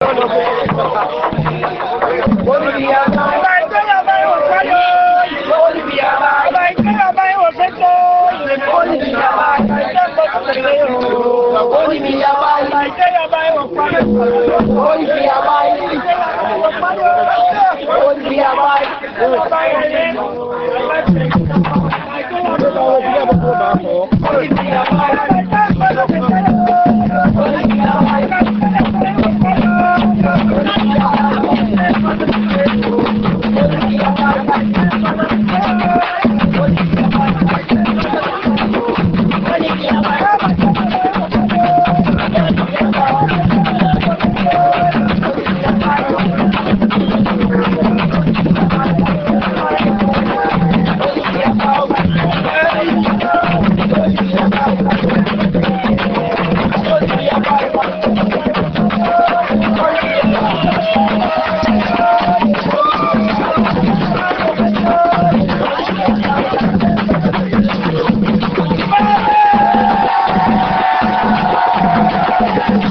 enregistrement durant une levée de deuil (Puubaaka)
danse : songe (aluku)
Genre songe
Pièce musicale inédite